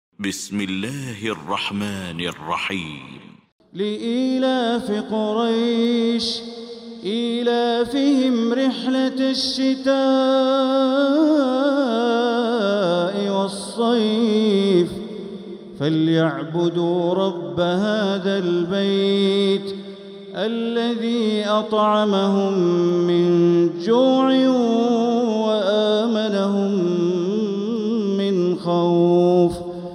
المكان: المسجد الحرام الشيخ: بدر التركي بدر التركي قريش